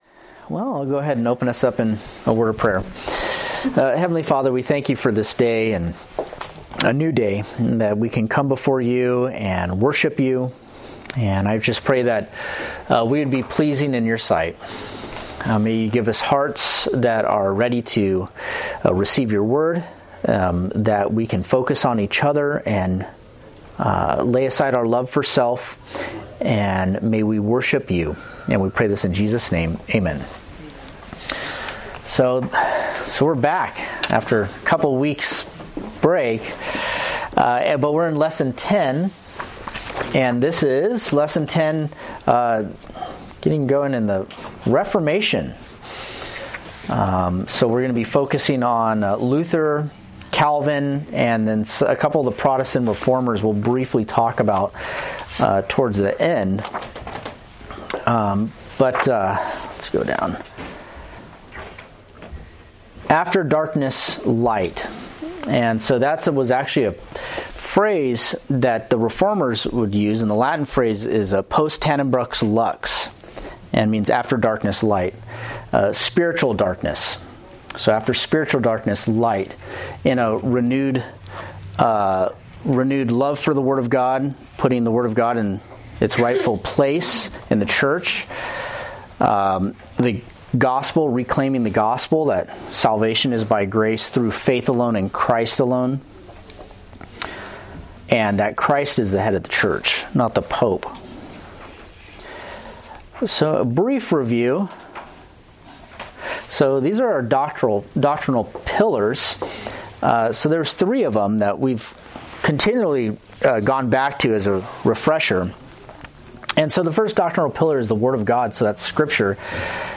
Date: Apr 13, 2025 Series: Forerunners of the Faith Grouping: Sunday School (Adult) More: Download MP3